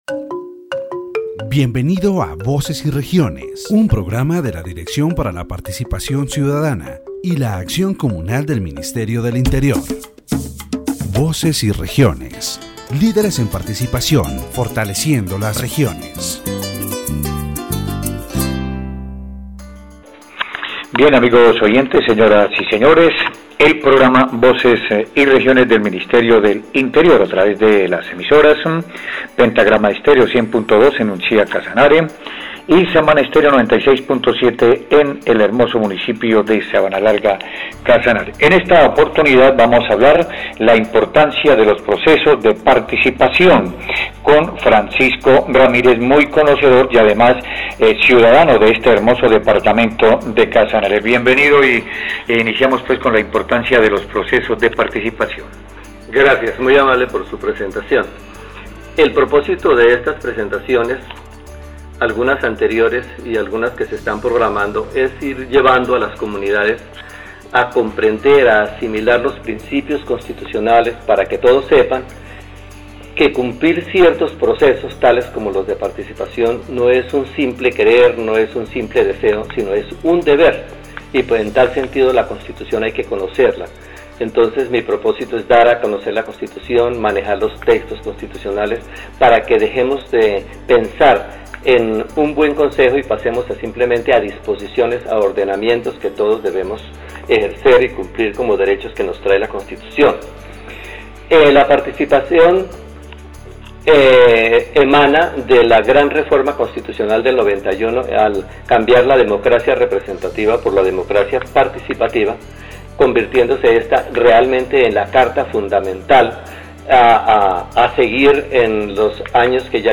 The radio program "Voces y Regiones" from the Ministry of the Interior, broadcasted through stations in Casanare, discusses the importance of participation processes in democracy.